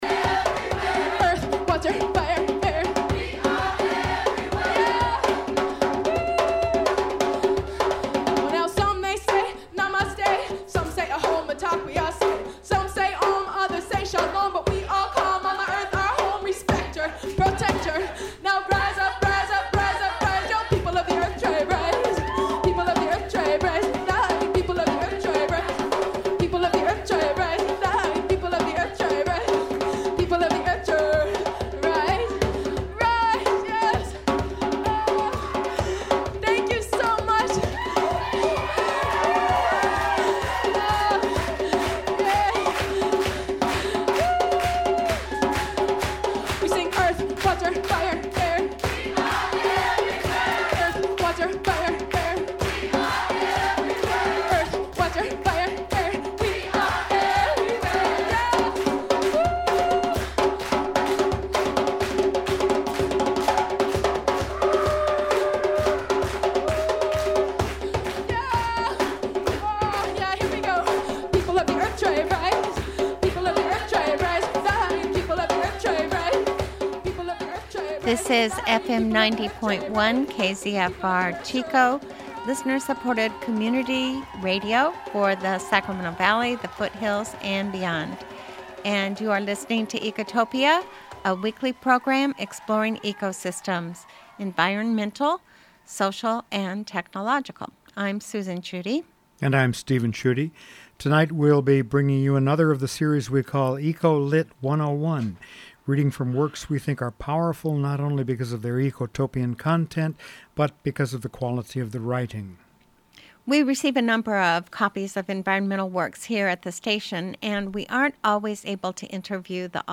Tonight on Ecotopia we’ll be bringing you another of the series we call “EcoLit”, reading from works we think are powerful not only because of their Ecotopian content, but because of the quality of the writing.